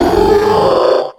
Cri de Séléroc dans Pokémon X et Y.